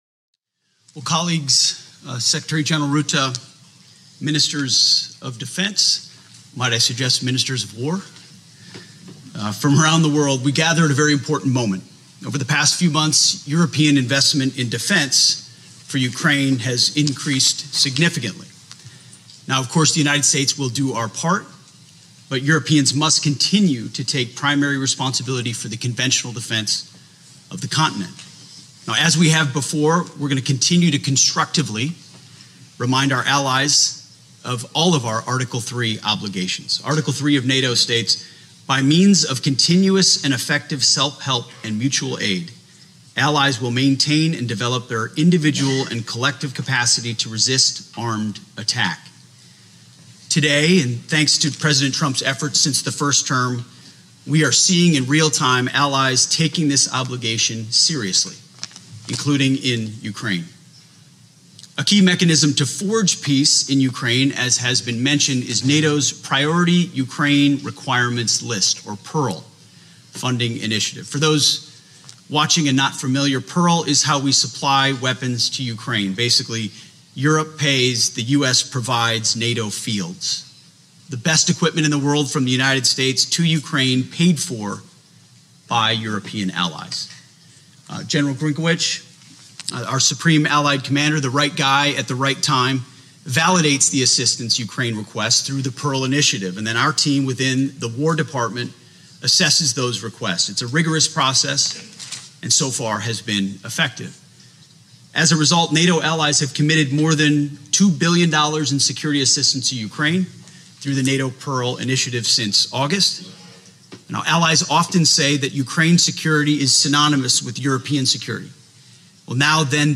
delivered 15 October 2025, NATO HQ, Brussels, Belgium
Audio Note: AR-XE = American Rhetoric Extreme Enhancement